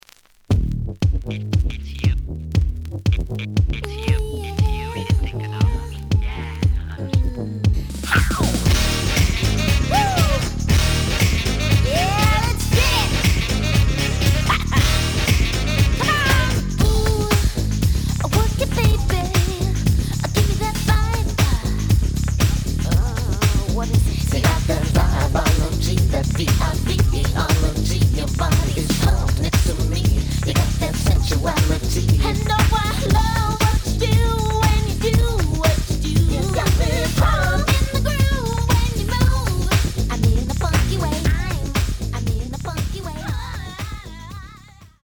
The audio sample is recorded from the actual item.
●Genre: House / Techno
Looks good, but slight noise on both sides.)